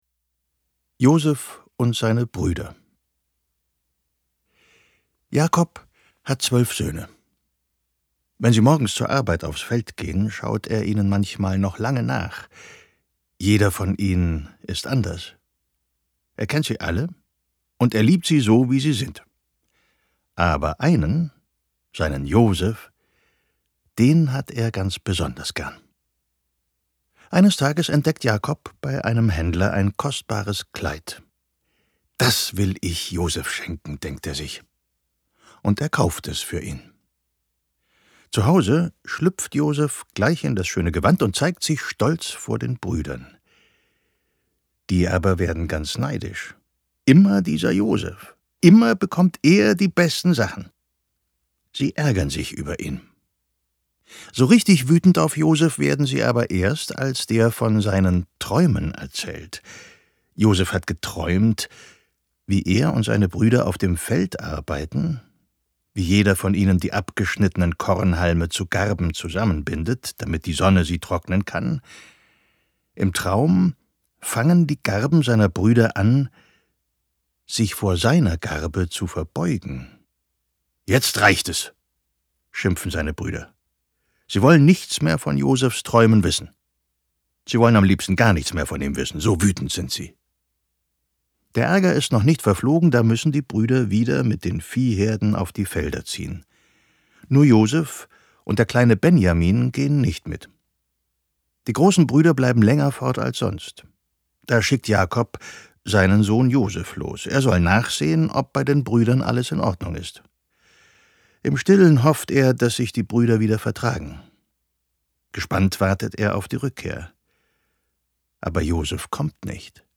Die erfolgreiche, neue Hörbuchreihe mit Geschichten aus der Bibel für Kinder.